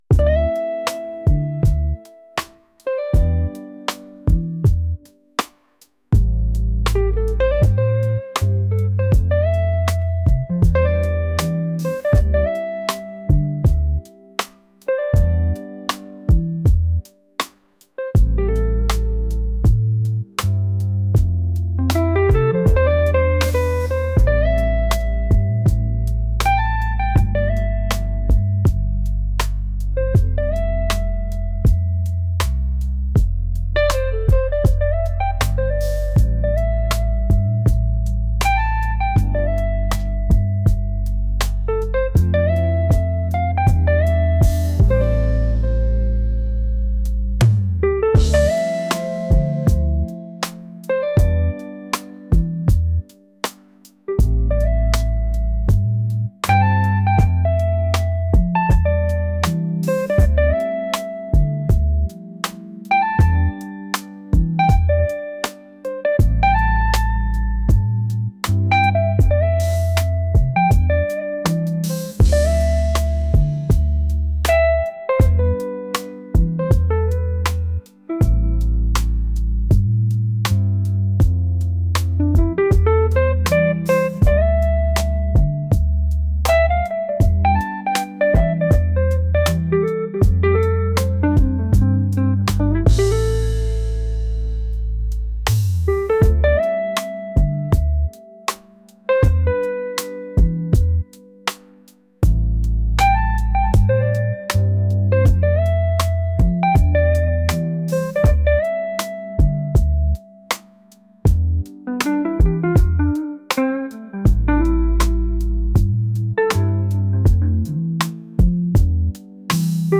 smooth | soulful